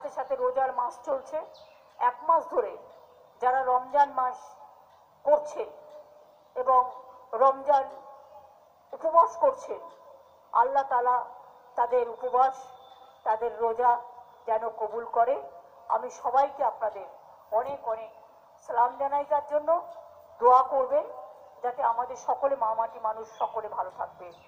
তিনি আজ (মঙ্গলবার) পশ্চিমবঙ্গের সিঙ্গুরে পথশ্রী-রাস্তাশ্রী প্রকল্পের উদ্বোধন অনুষ্ঠানে বক্তব্য রাখার সময়ে ওই মন্তব্য করেন।